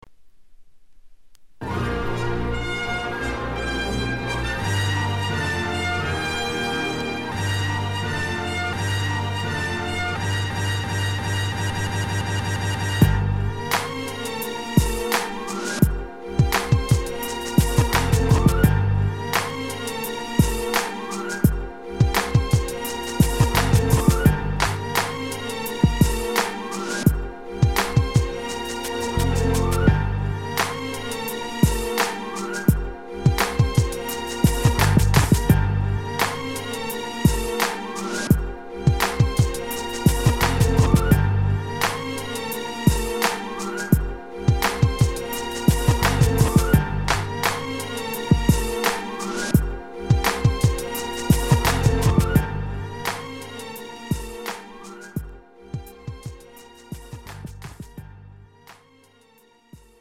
장르 pop 구분 Premium MR